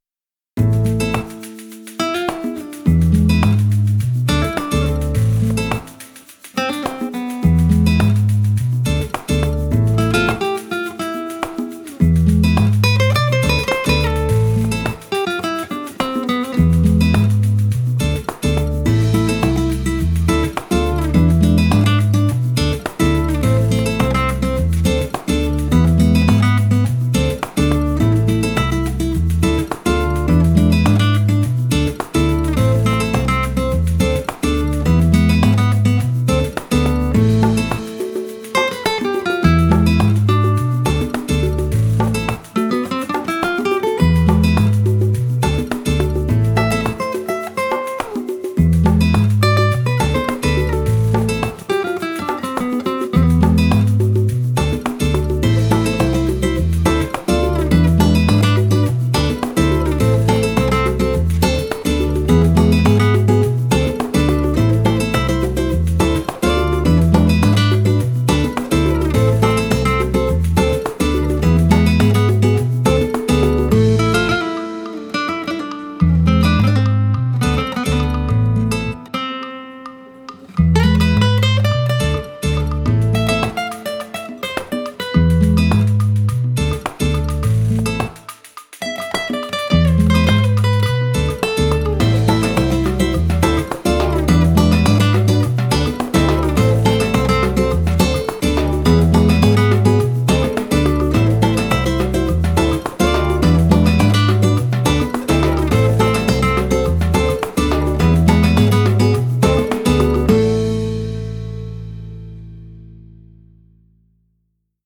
Spanish-Classic-Guitar.mp3